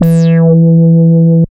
71.04 BASS.wav